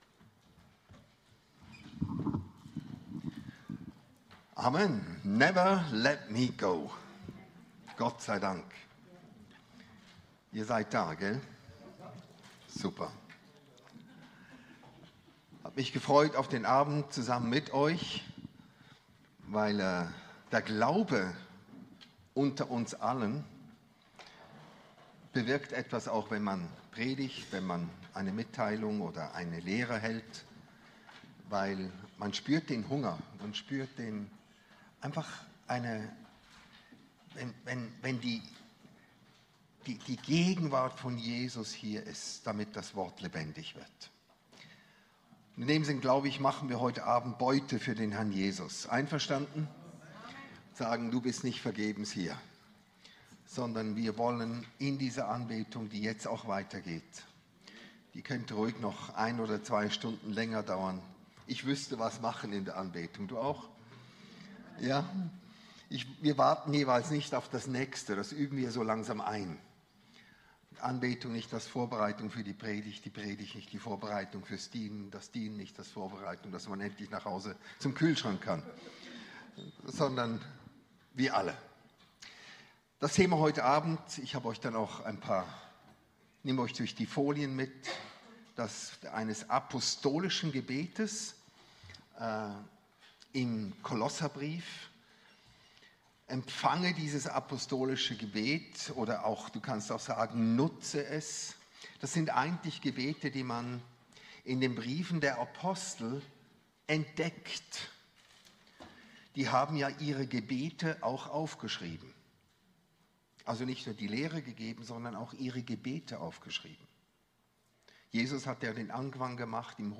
Dienstagsgottesdienst vom 4.